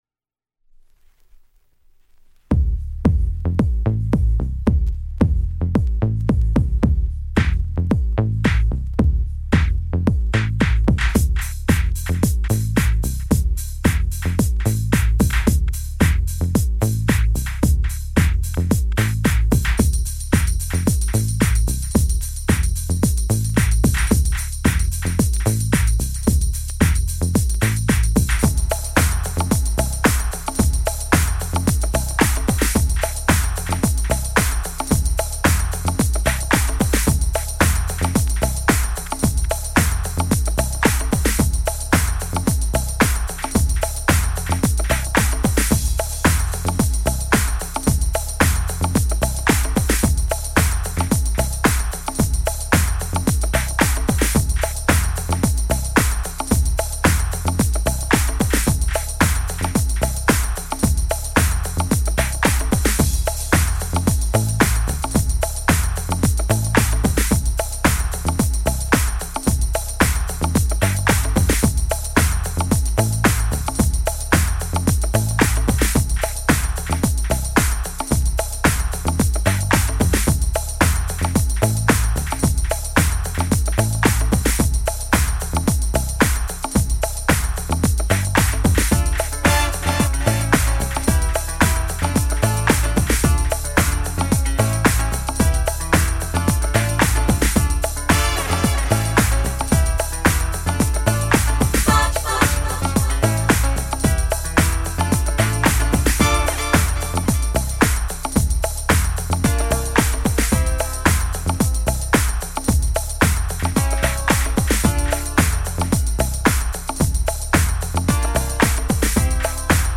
Early House / 90's Techno
(Instrumental)